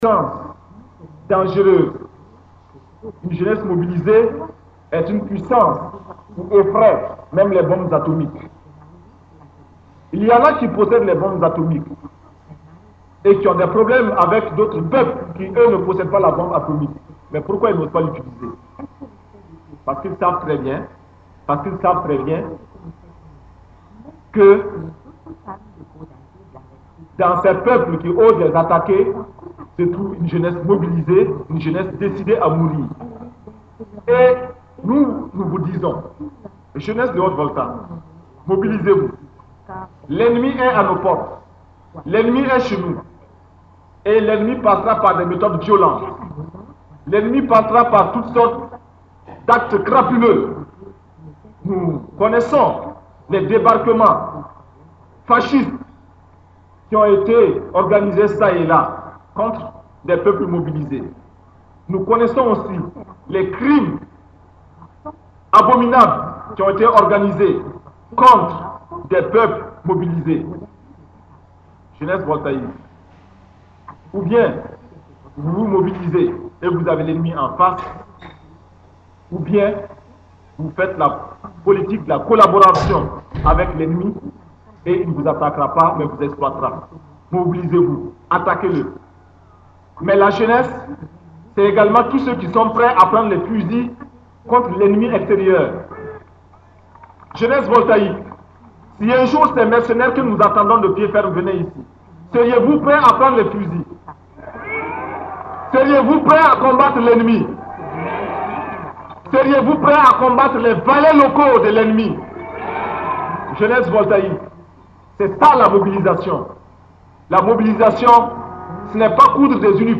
Discours du premier ministre Thomas Sankara à l’attention de la jeunesse le 14 mai 1983 à Bobo (audio) - Thomas Sankara Website - Officiel